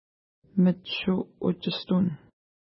Image Not Available ID: 42 Longitude: -59.4458 Latitude: 52.7223 Pronunciation: mətʃu:-utʃistun Translation: Eagle's Nest Feature: lake Explanation: There are probably a lot a muskrats (eaten by the eagle) in this lake.